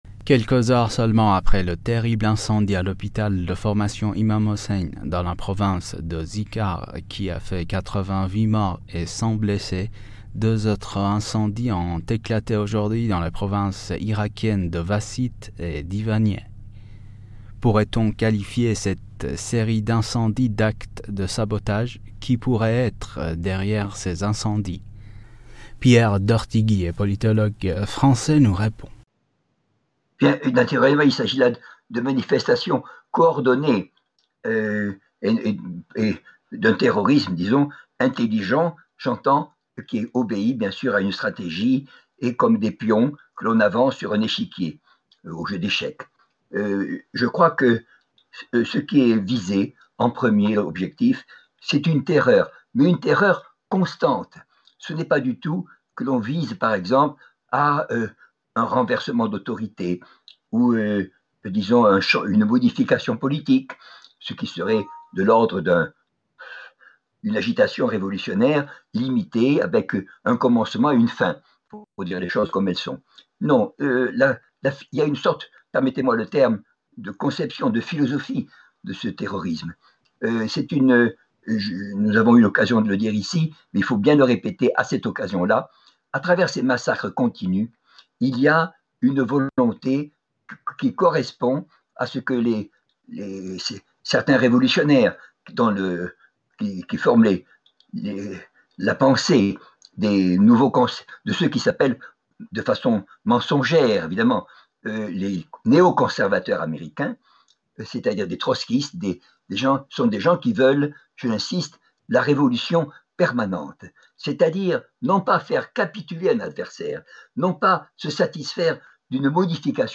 politologue s’exprime sur le sujet.